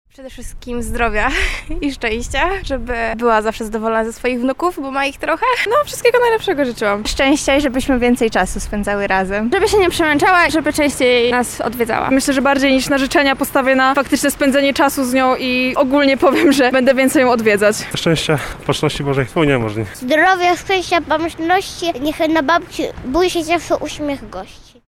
[SONDA] Jak obchodzimy Dzień Babci?
Zapytaliśmy mieszkańców Lublina czego życzyli swoim babciom w ich szczególnym dniu: